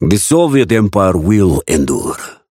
Raven voice line - The Soviet Empire will endure.